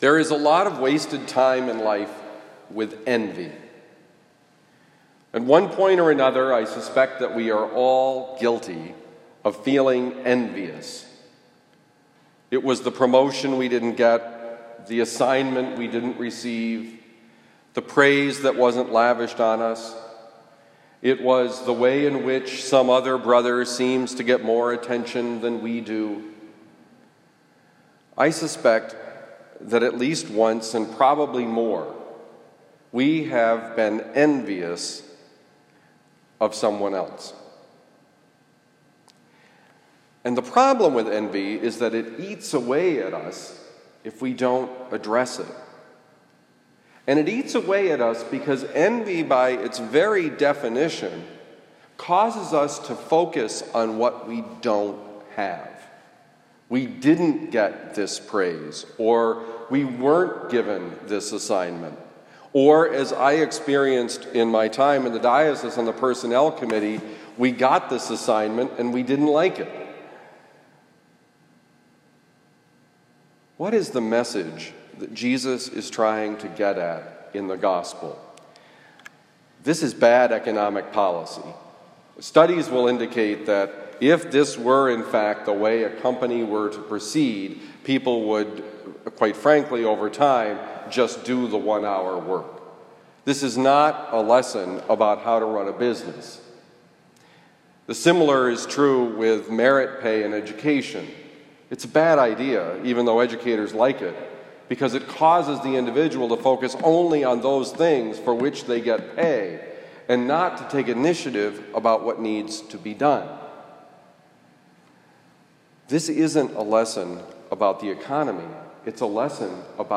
Homily given at Saint Dominic Priory, September 20, 2020